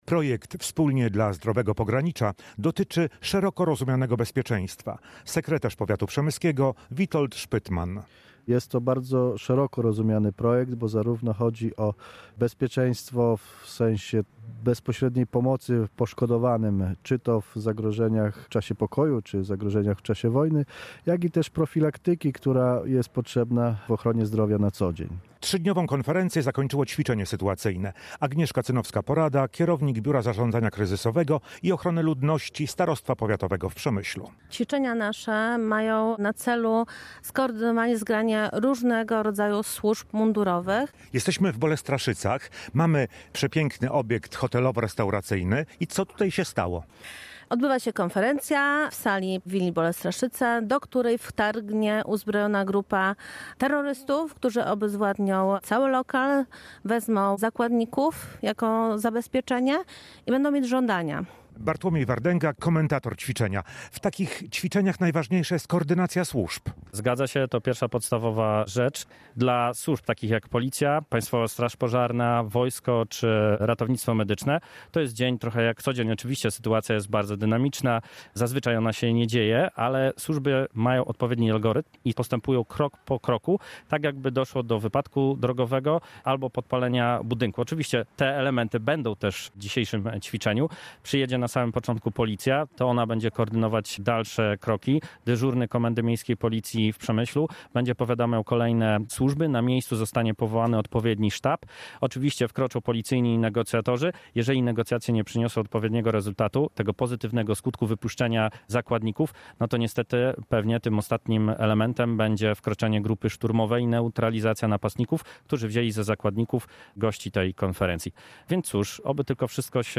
Cwiczenia-Bolestraszyce.mp3